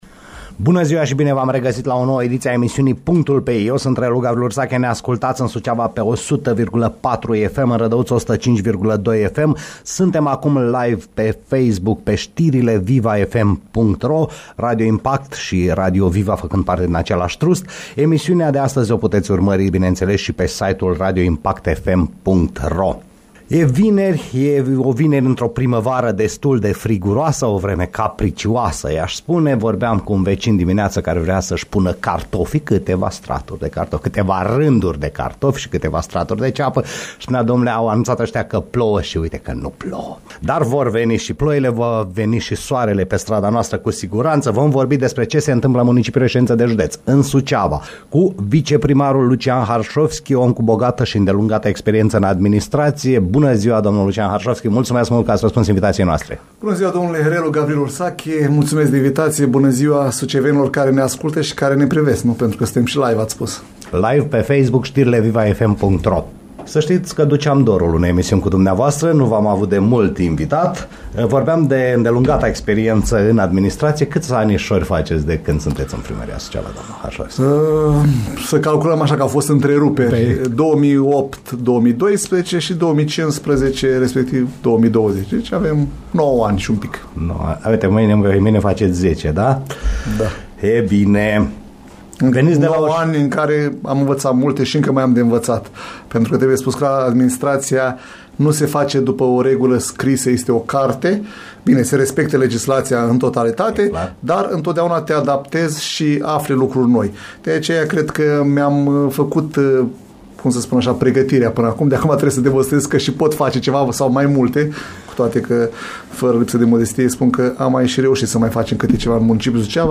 Viceprimarul Lucian Harșovschi live la PUNCTUL PE I